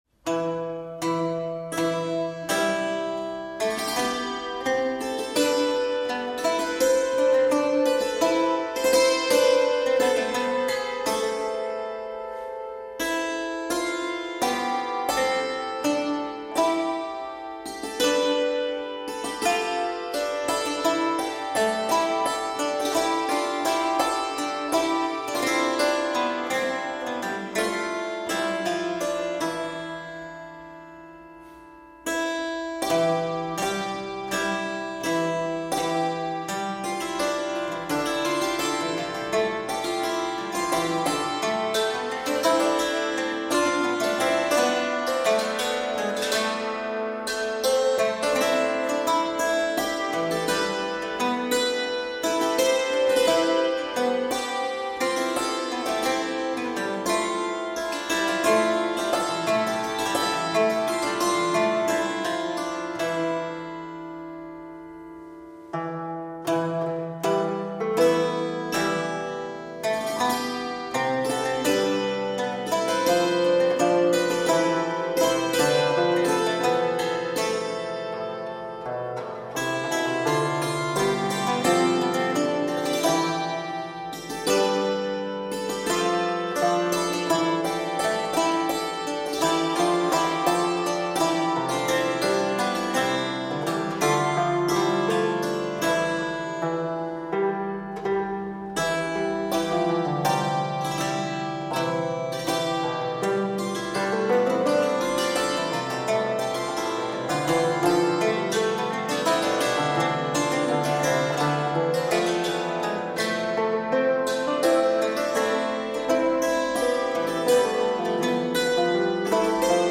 Ecco quindi che l’ensemble Anonima Frottolisti prosegue la sua ricerca in una profonda introspezione nei meandri della musica dell’Umanesimo, focalizzandosi su di un repertorio attinto dalla lettere della Marchesa di Mantova Isabella d’Este , la cui spiccata sensibilità culturale e artistica segnò profondamente la moda dell’epoca. Al microfono